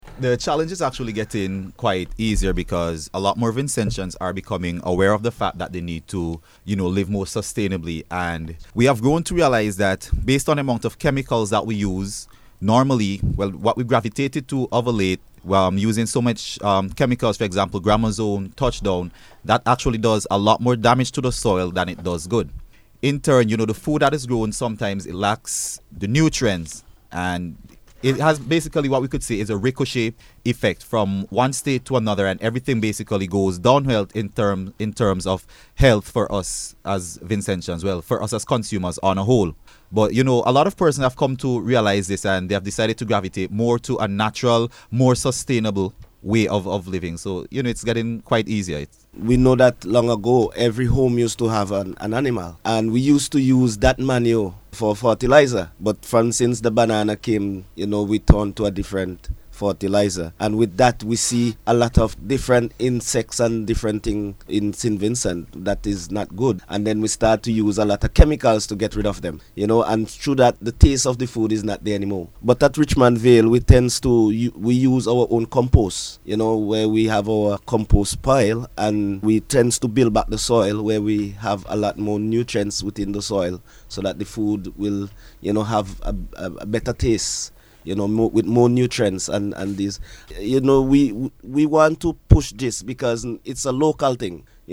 He was speaking on NBC’s Views On issues programme on Sunday, which focused on the topic: ‘Green Living’.